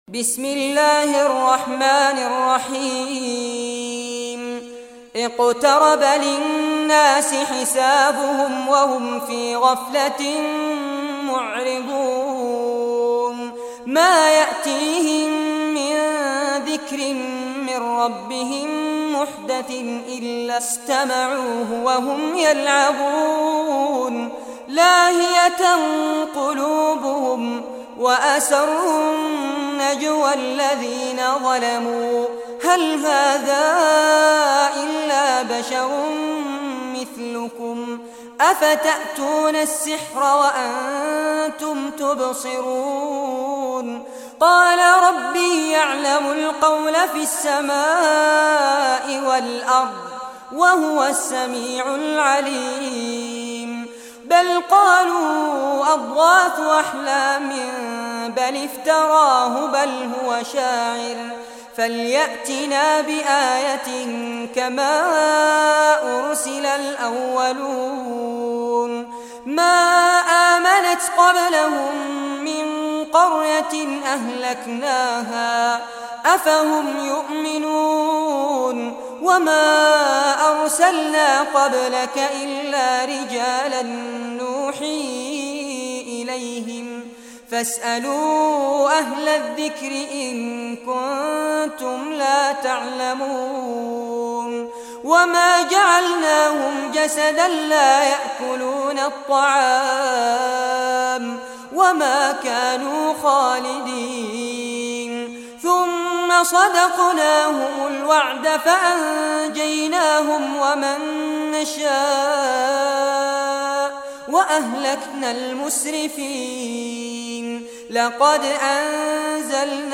Surah Al-Anbya Recitation by Fares Abbad
Surah Al-Anbya, listen or play online mp3 tilawat / recitation in Arabic in the beautiful voice of Sheikh Fares Abbad.